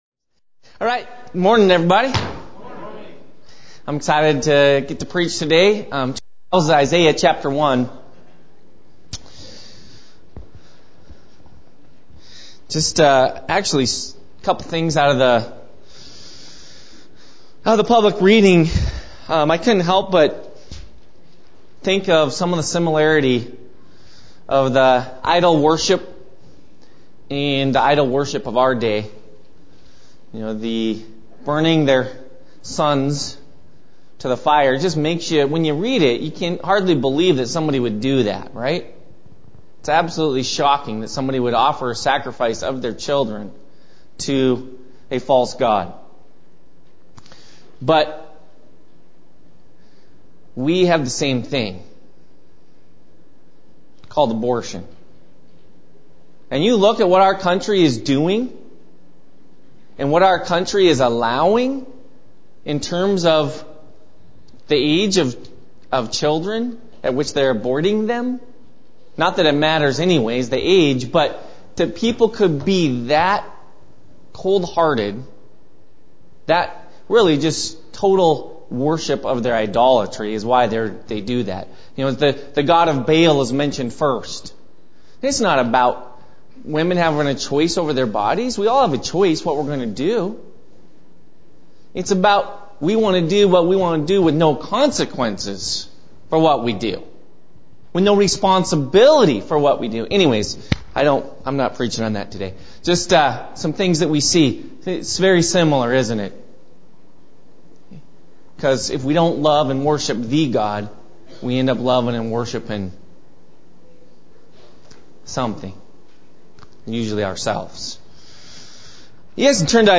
Series: Morning Messages Tagged with world view , reason , emotions , belief , guilt , truth , confidence